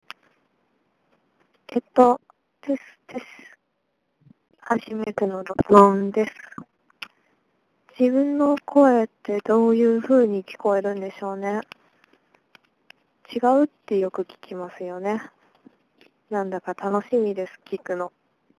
電話でブログ投稿〜BLOGROWN: